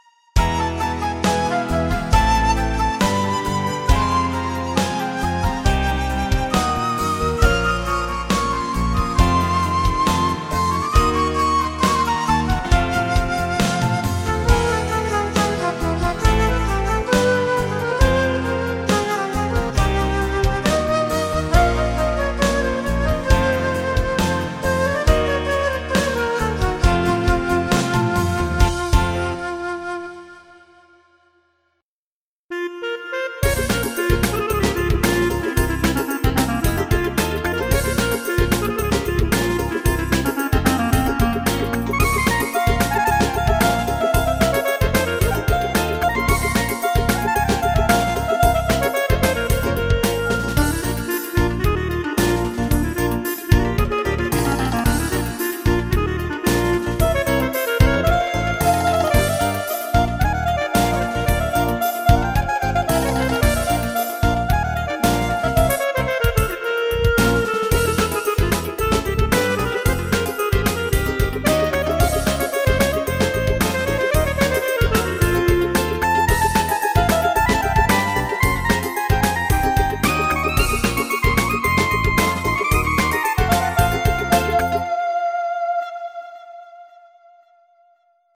זהו שמו של הניגון הכלייזמרי המדהים ביופיו ובהרמוניותייו....
(הוא גם מנגן בסרטון המצורף) שמעו ותחי נפשכם!